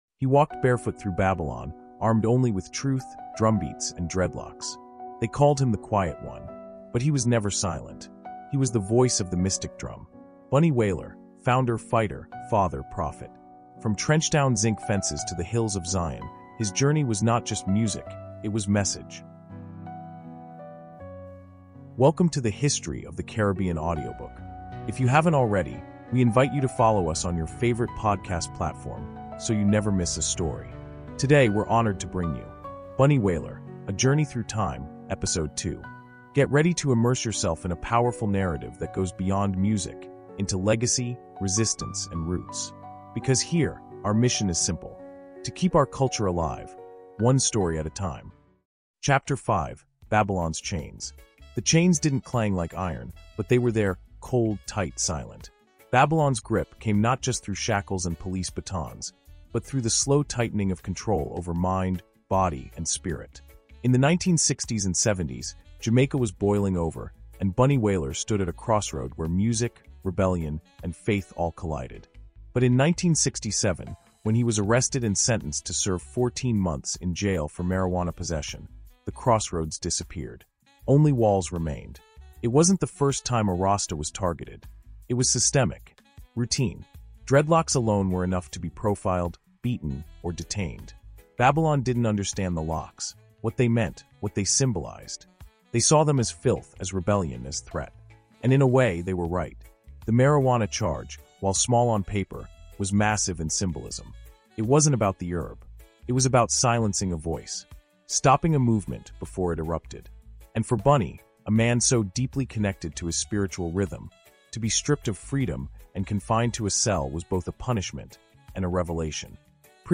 Bunny Wailer: Voice of the Mystic Drum is a powerful 16-chapter audiobook chronicling the life of reggae legend Neville “Bunny” Livingston—co-founder of The Wailers, spiritual guardian of roots reggae, and the last lion of a cultural revolution. From Nine Mile to Trenchtown, from spiritual exile to global prophecy, this immersive storytelling journey reveals the heart, fire, and message of a man who never bent to Babylon.